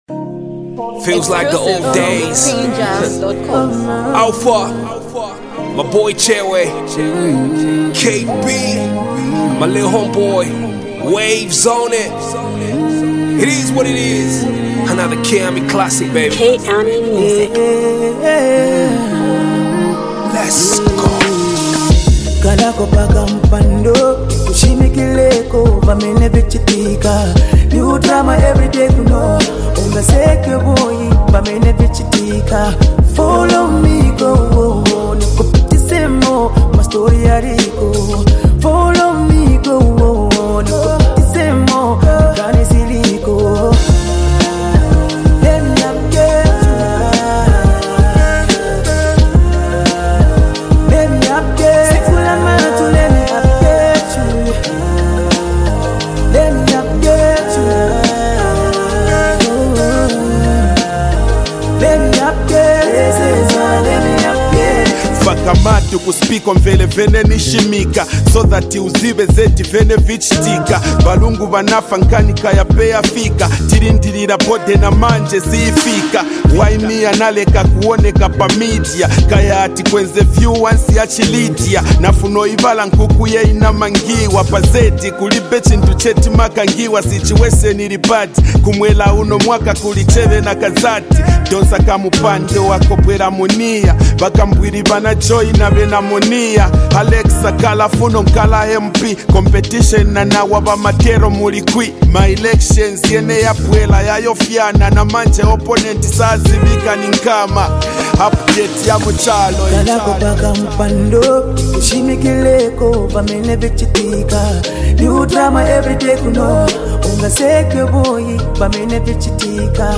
Zambian music